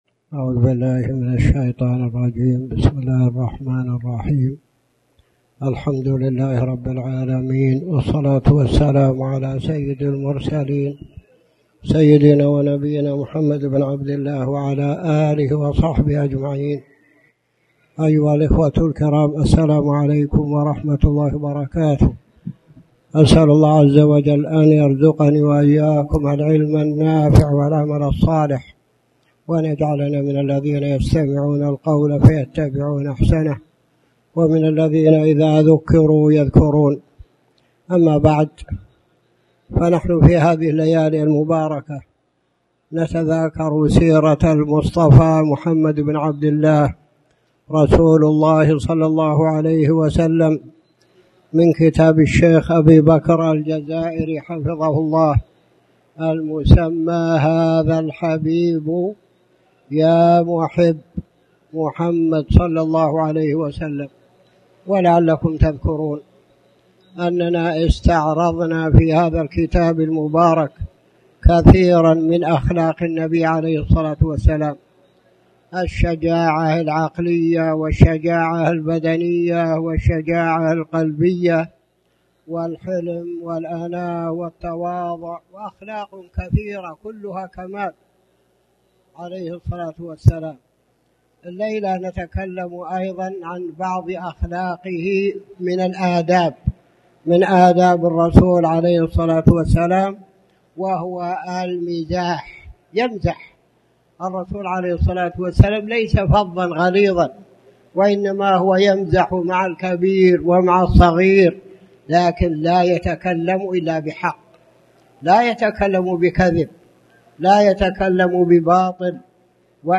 تاريخ النشر ١٣ محرم ١٤٣٩ هـ المكان: المسجد الحرام الشيخ